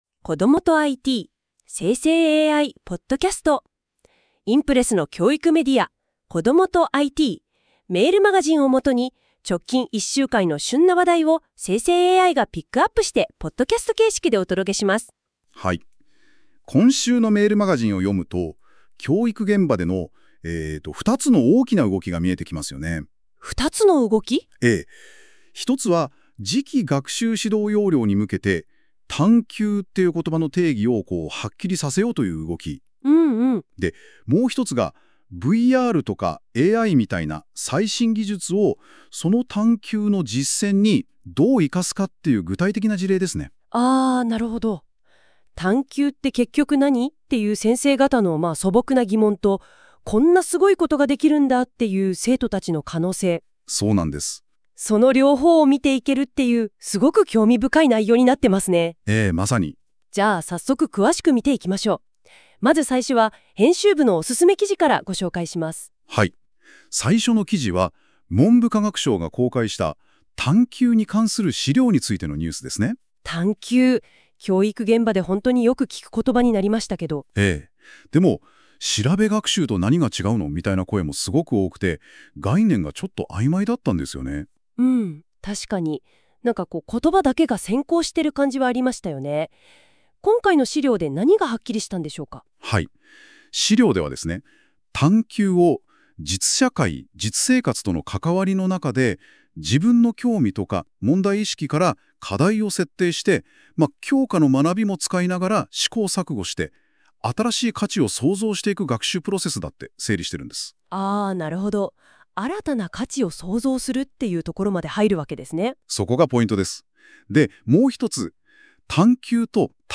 ※生成AIによる読み上げは、不自然なイントネーションや読みの誤りが発生します。 ※この音声は生成AIによって記事内容をもとに作成されています。